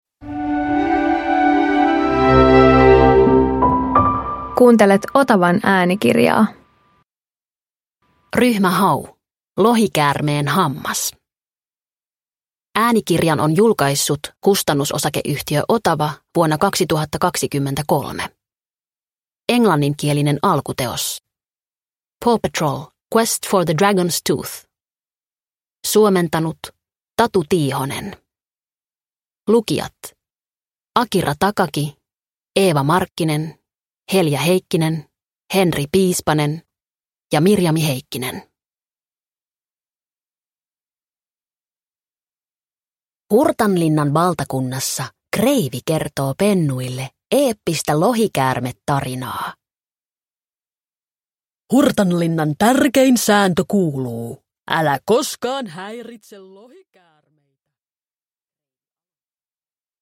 Ryhmä Hau - Lohikäärmeen hammas – Ljudbok – Laddas ner